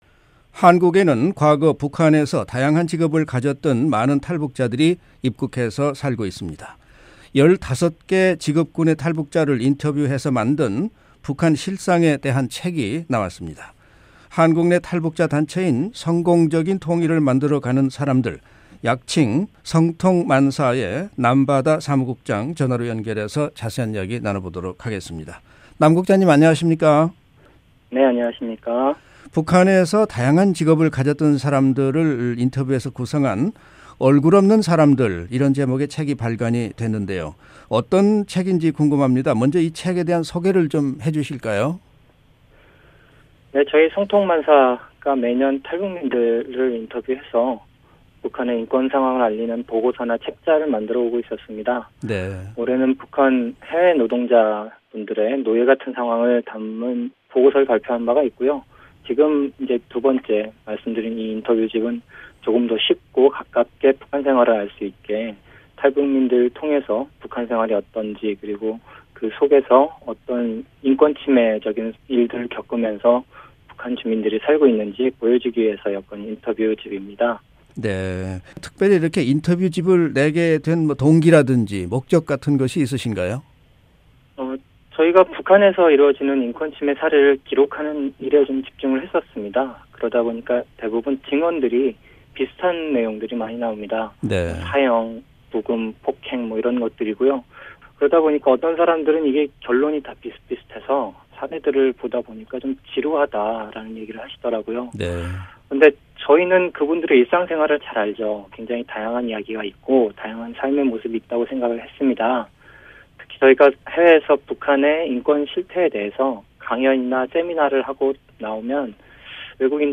[인터뷰]